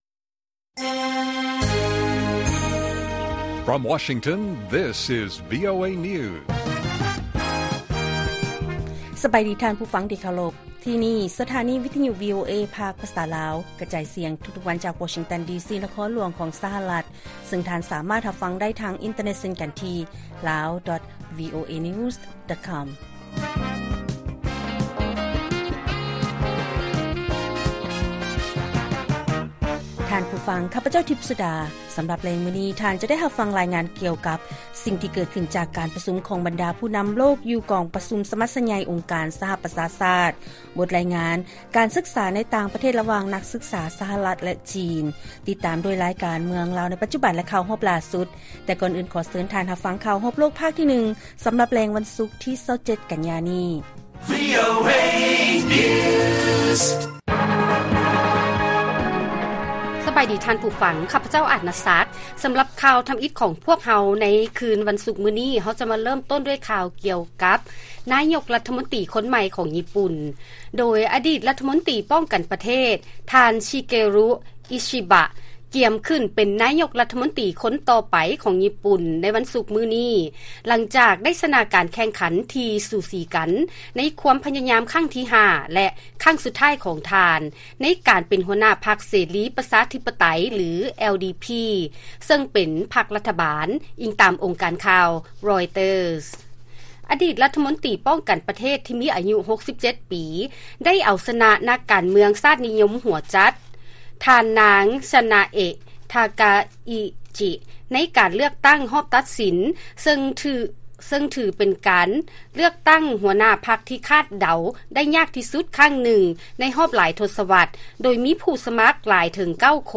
ລາຍການກະຈາຍສຽງຂອງວີໂອເອລາວ: ສະມາຊິກສະພາ ທີ່ເປັນນັກລົບເກົ່າ ທ່ານ ອິຊິບະ ກຽມຮັບຕໍາແໜ່ງ ນາຍົກລັດຖະມົນຕີຂອງຍີ່ປຸ່ນ